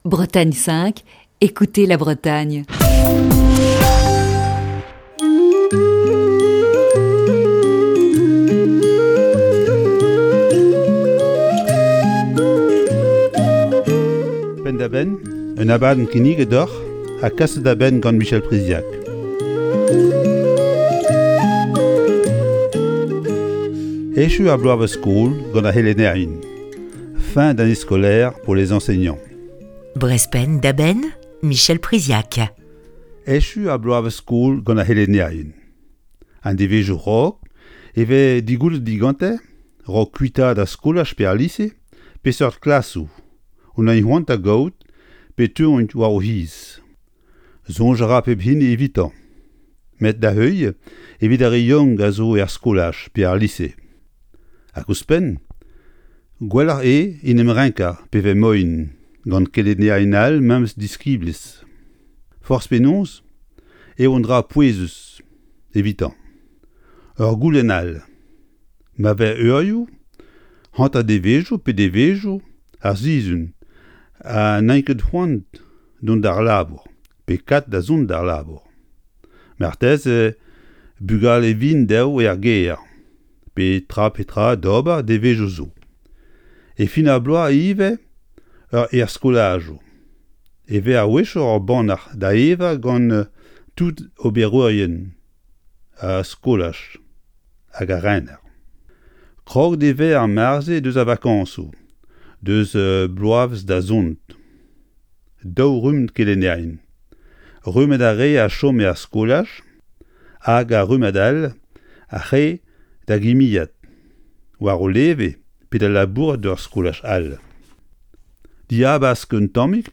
Chronique du 29 juin 2021.